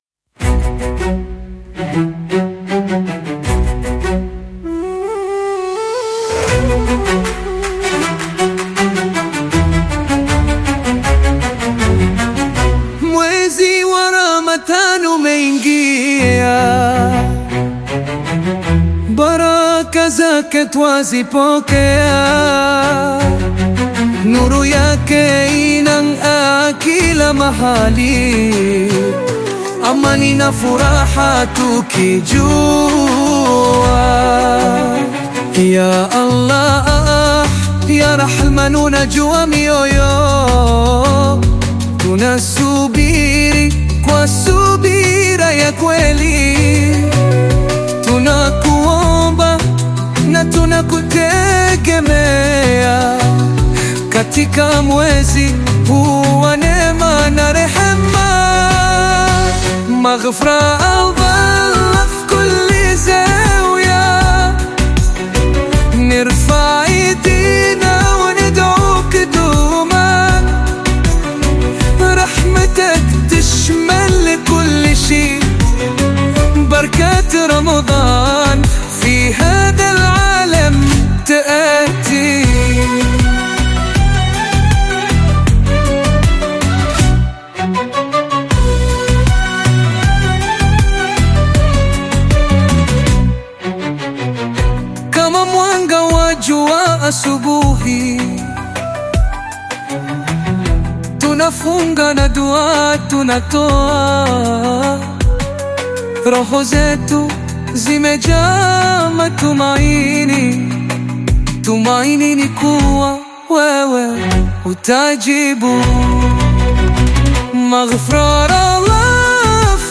Qaswida music track
Qaswida song